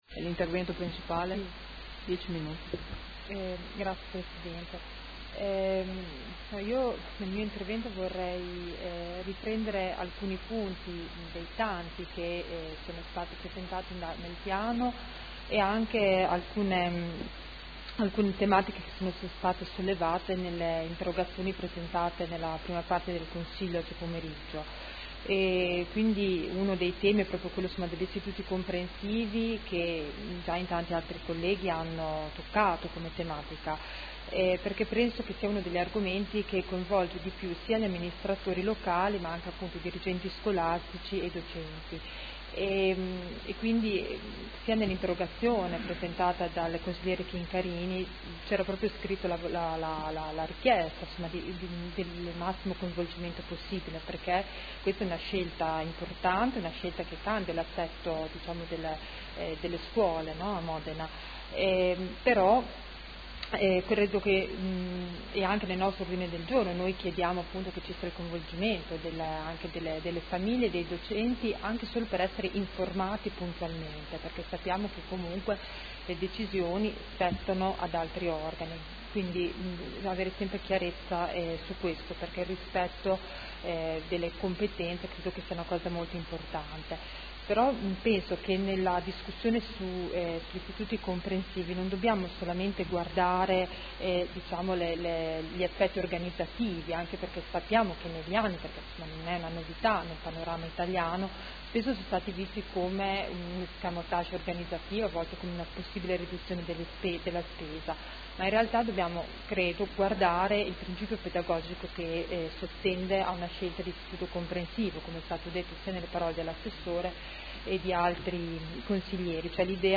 Seduta del 1° ottobre. “Educare Insieme” Piano della Buona Scuola a Modena.